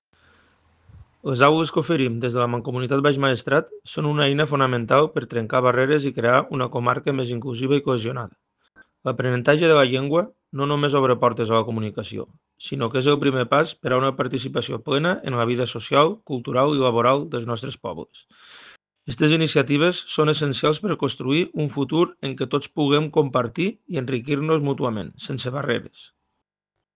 El president de la Mancomunitat Baix Maestrat, Juan José Cabanes Ramón, ha destacat que:
TALL-DE-VEU-PRESIDENT.ogg